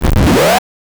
Session 11 - SFX.wav